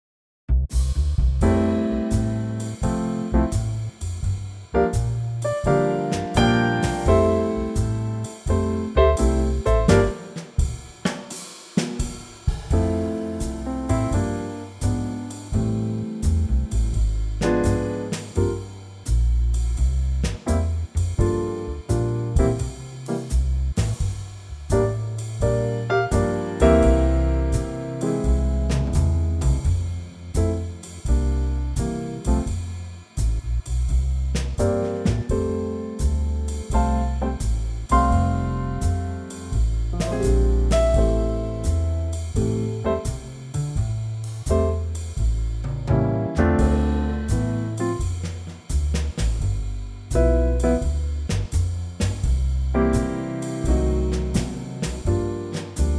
lightly swinging in tempo, without the verse.